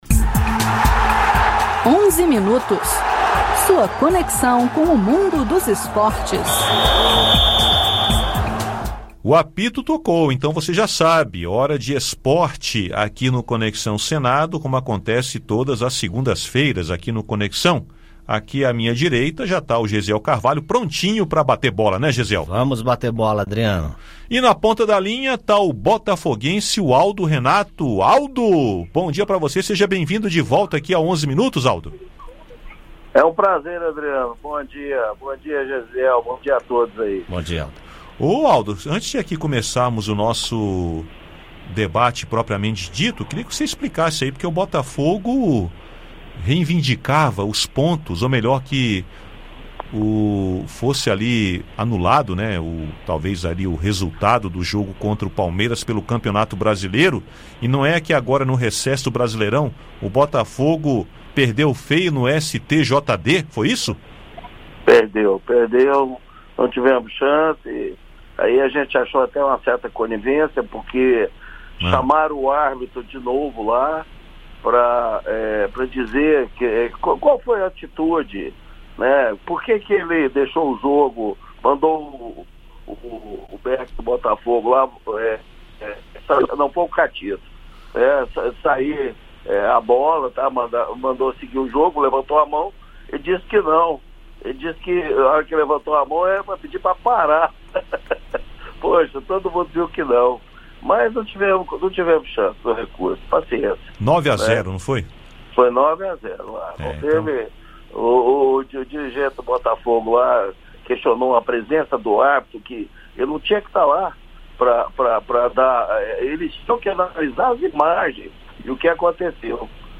Ouça o áudio com a entrevista e os comentários sobre o futebol e a eliminação do Brasil pela França na Copa do Mundo de Futebol Feminino.